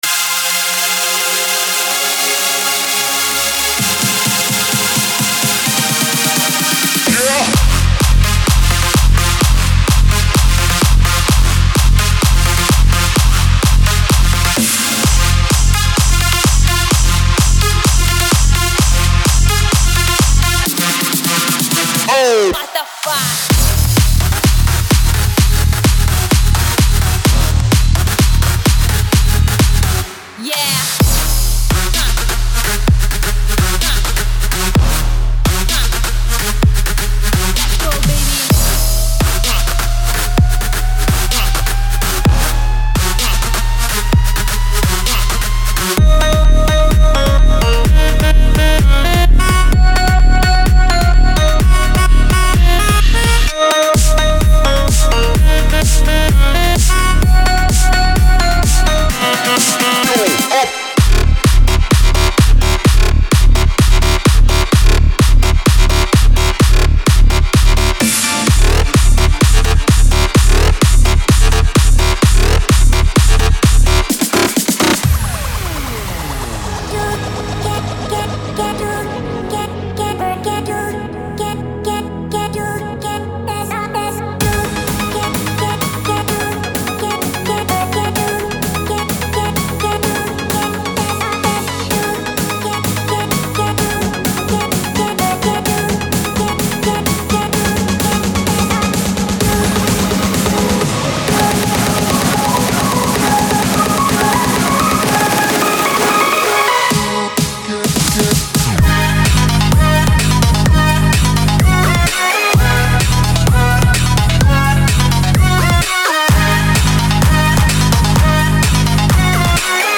整合了总共70G的包装非常适用于Hard，House，BigRoom等风格电子音乐制作